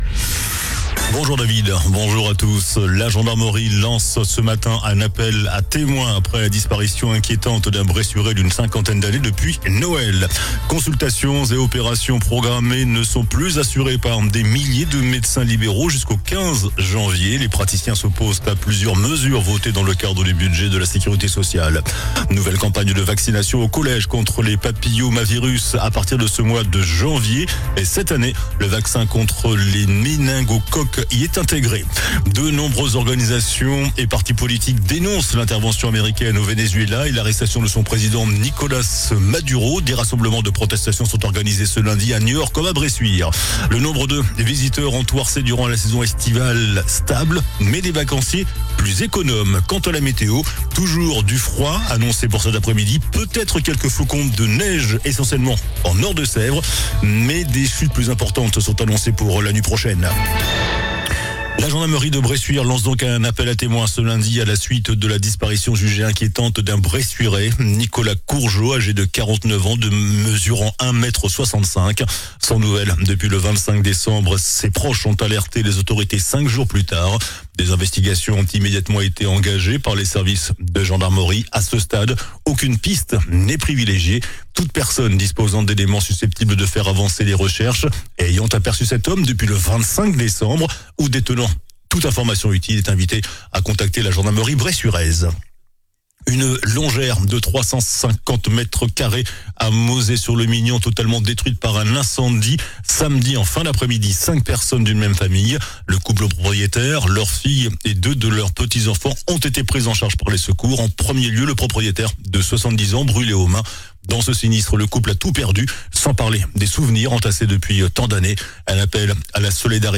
JOURNAL DU LUNDI 05 JANVIER ( MIDI )